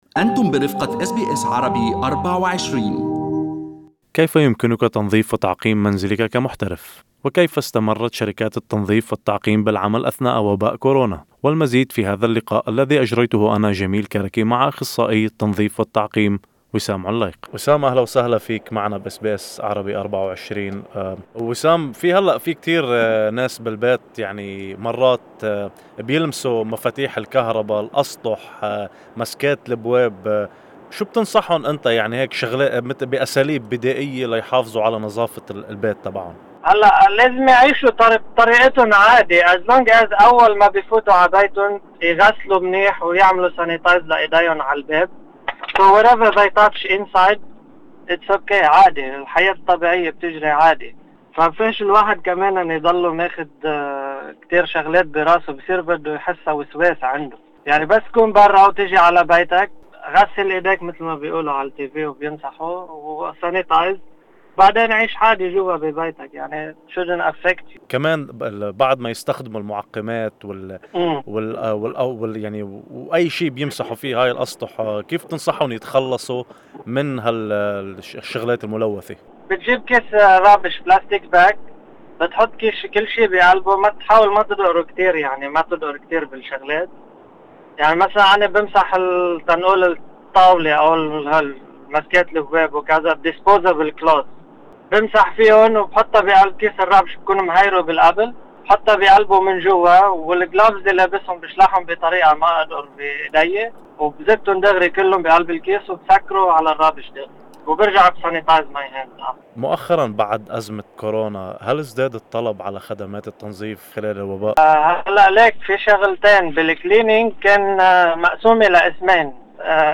والمزيد في هذا اللقاء الصوتي.